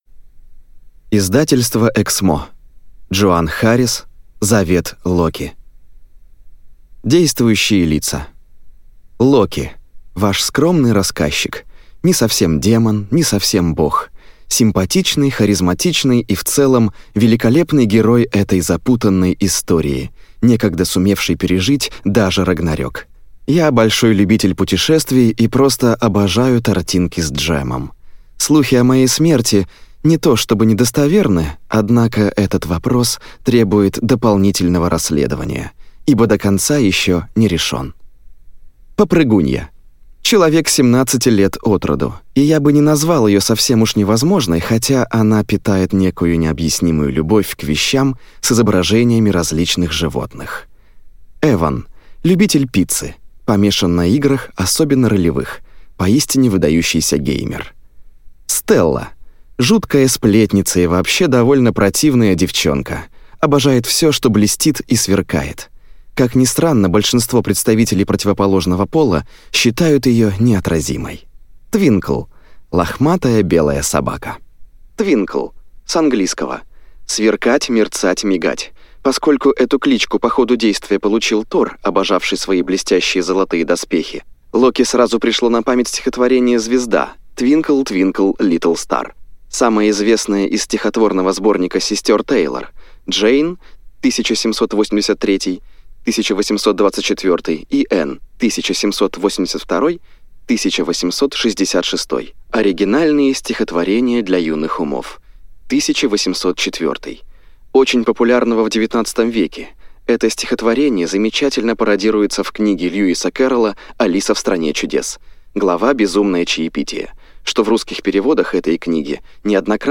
Аудиокнига Завет Локи - купить, скачать и слушать онлайн | КнигоПоиск